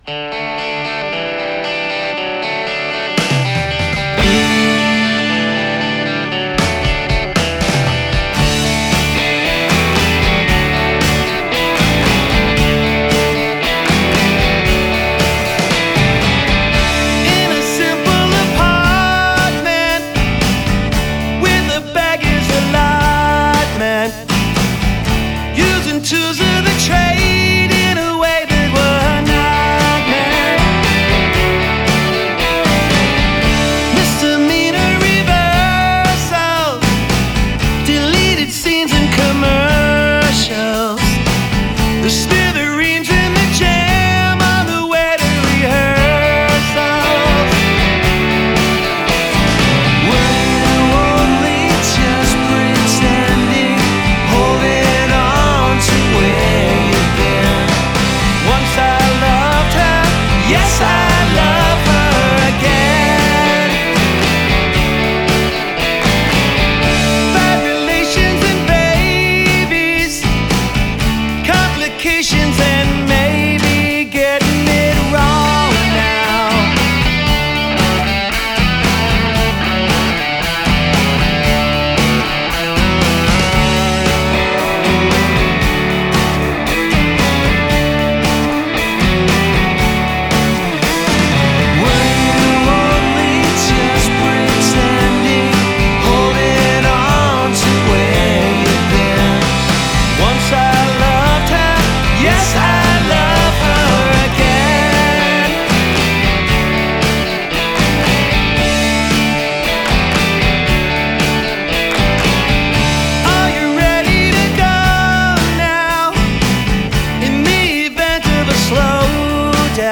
staccato groove
with it’s great lead lines and background vocals